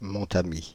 Montamy (French pronunciation: [mɔ̃tami]
Fr-Montamy.ogg.mp3